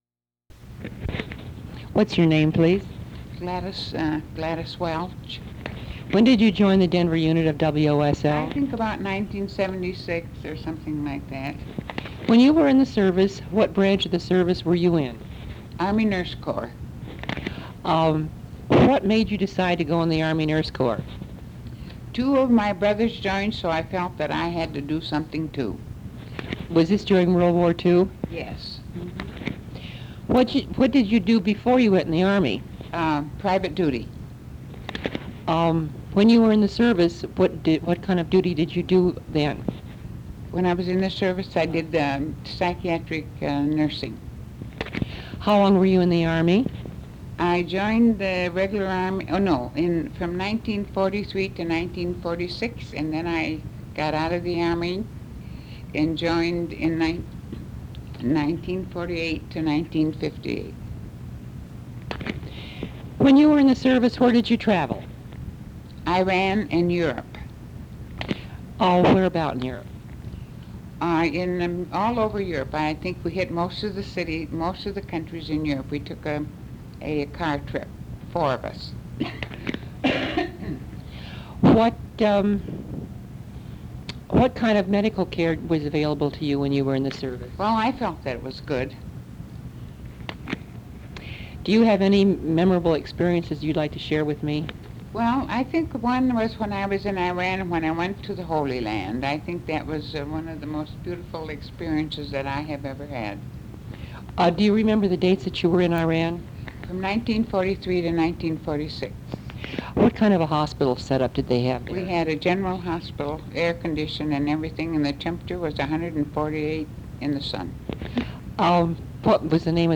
Women's Overseas Service League Oral History Project
Interviews